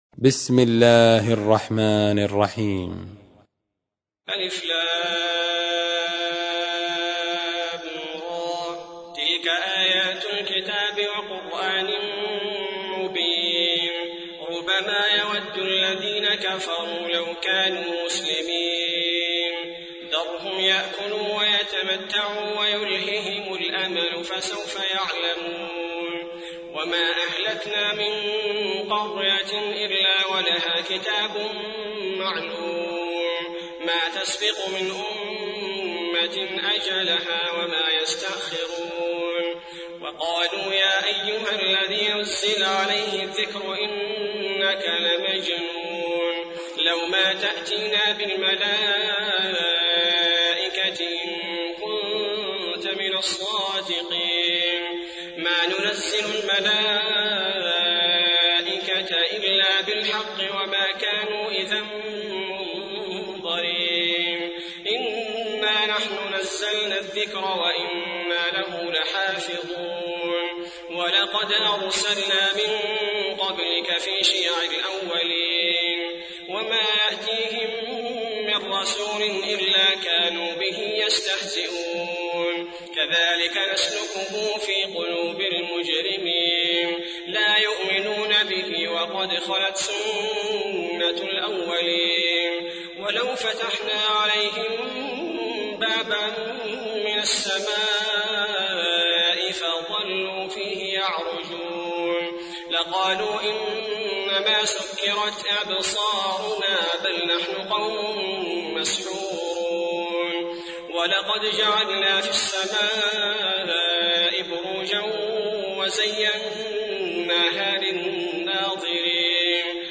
تحميل : 15. سورة الحجر / القارئ عبد البارئ الثبيتي / القرآن الكريم / موقع يا حسين